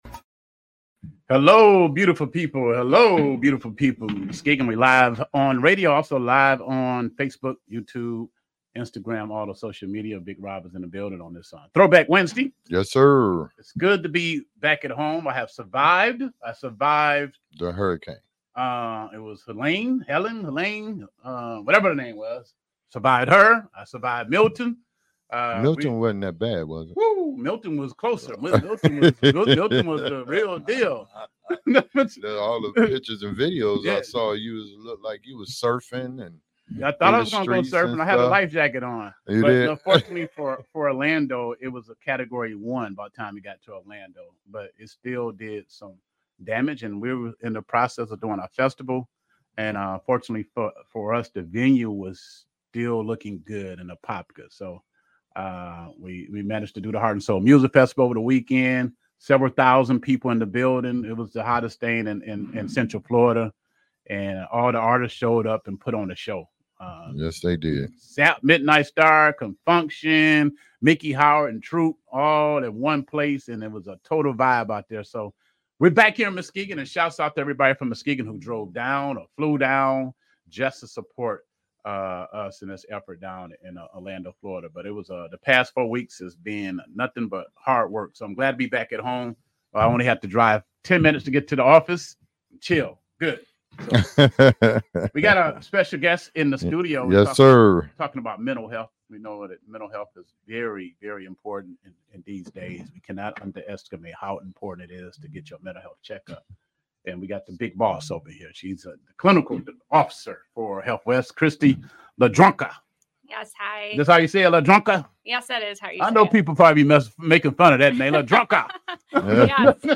Interview with Healthwest